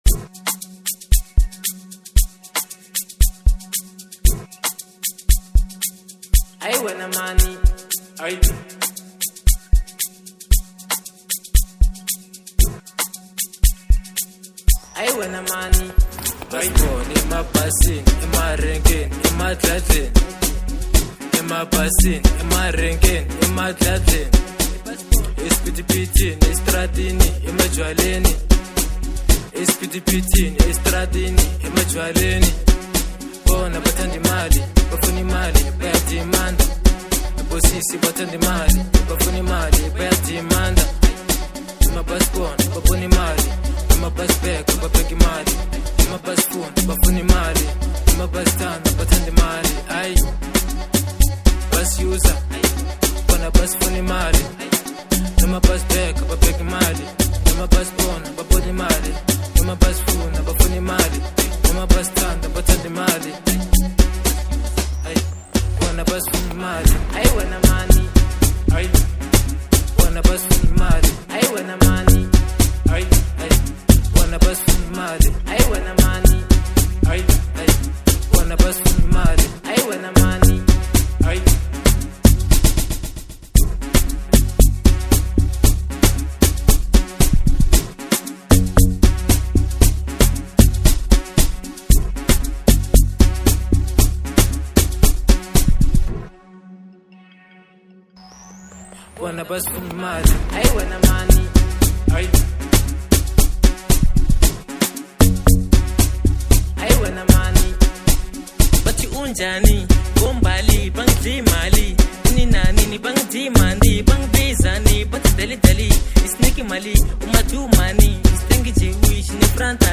04:33 Genre : Amapiano Size